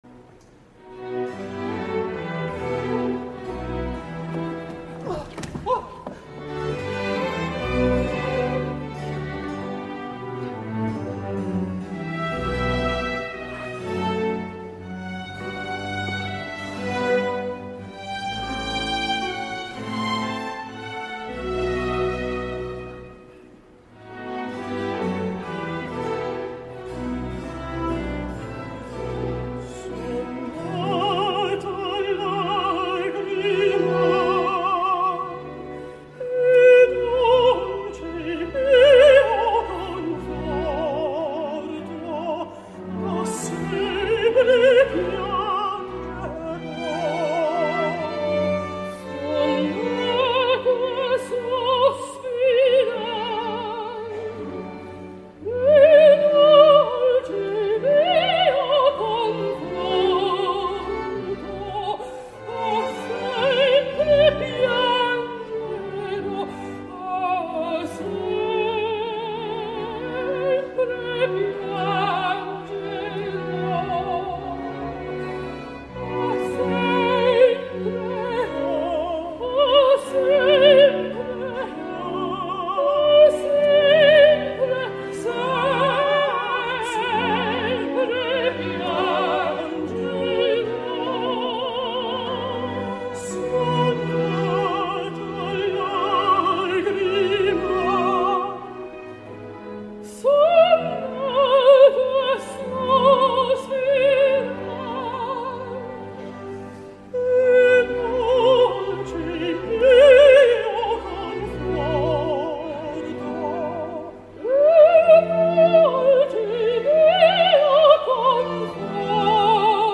No és una contralt en stricto sensu, però s’apropa molt i bé, a la vocalitat i sonoritats més greus de la corda femenina.
Sensacional el Sesto de Alice Coote, la mezzosoprano nord-americana que tan còmode se sent en els rols transvestits, exhibeix una extensió vocal admirable, acompanyada d’una exquisida musicalitat. Com acostuma a succeir quan la Cornelia i el Sesto són de categoria, el catàrtic duo “Son nata a lagrimar” que tanca el primer acte, va esdevenir un dels millors moments de tota la representació.
Cornelia: Patricia Bardon
Sesto: Alice Coote